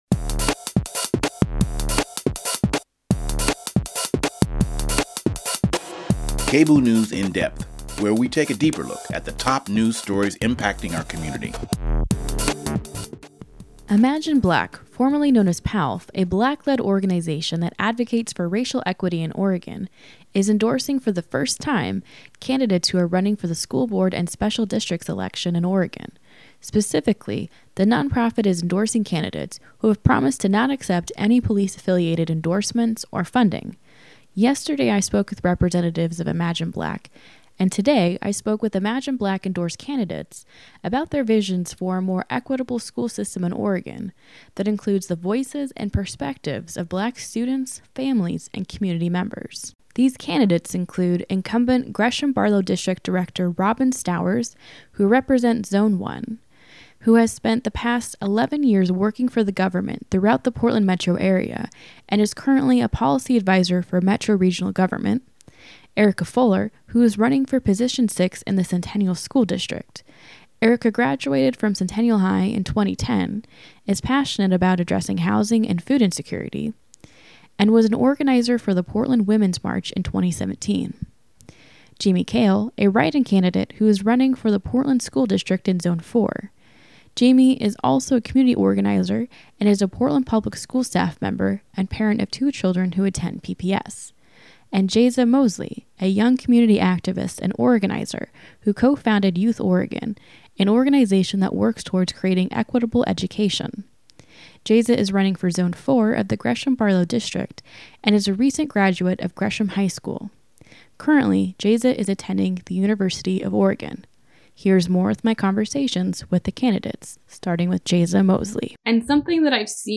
News In Depth